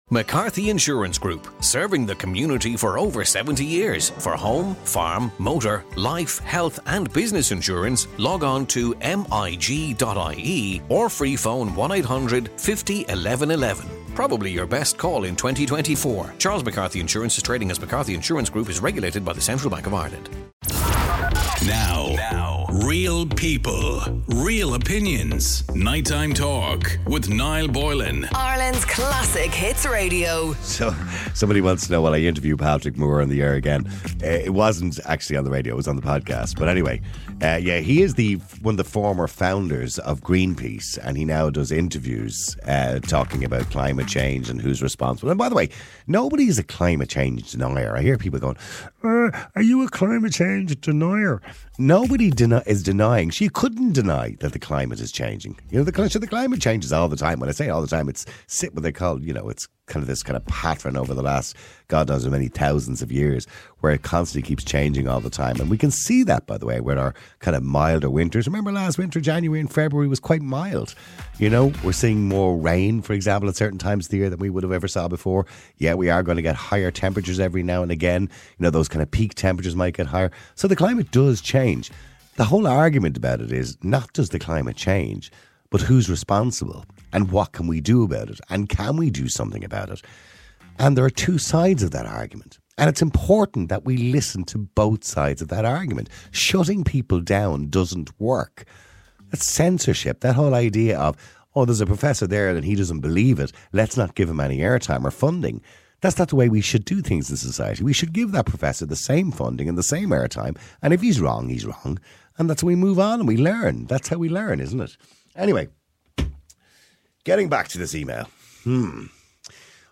A radio talk show that cares about YOU